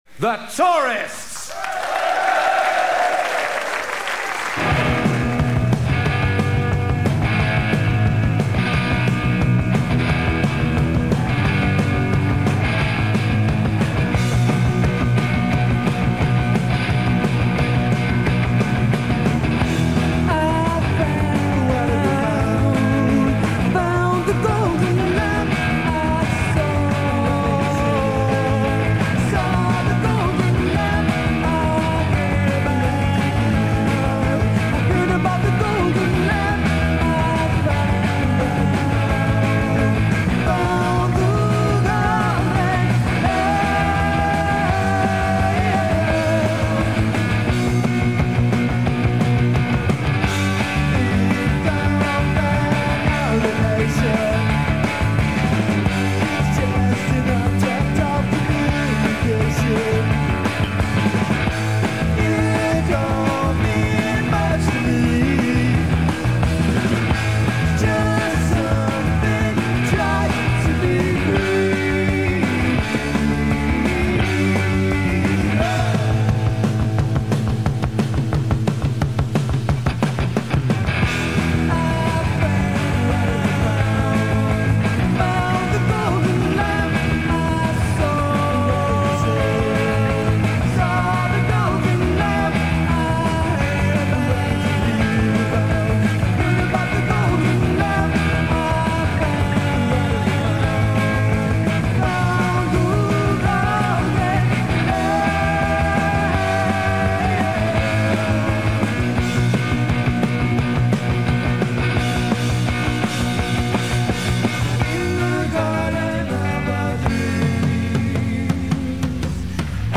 Recorded live at the Paris Theatre
Live At The Paris Theatre, London
folk rock band